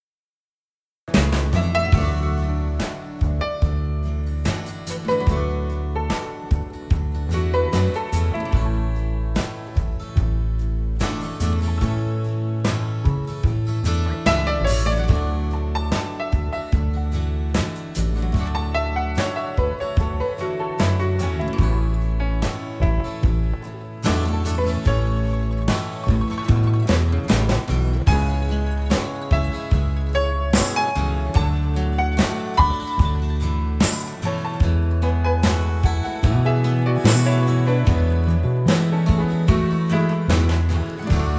[Piano Solo]